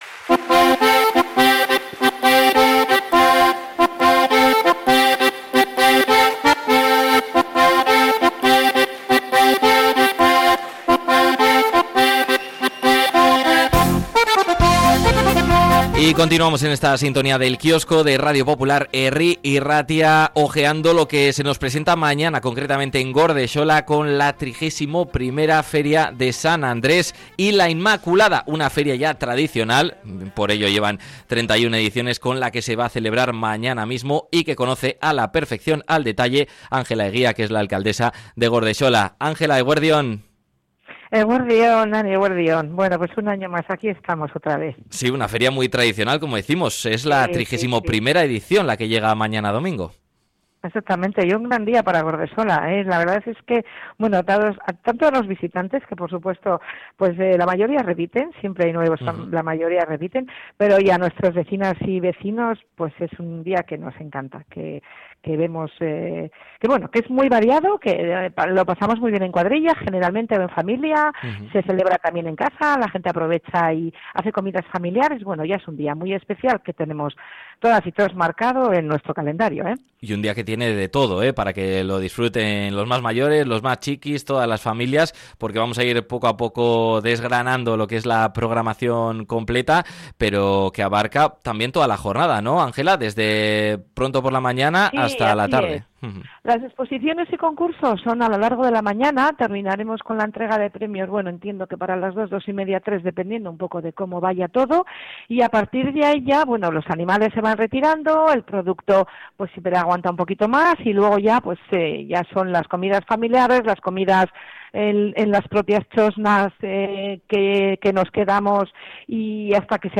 La alcaldesa Ángela Egia nos detalla la programación de este domingo 1 de diciembre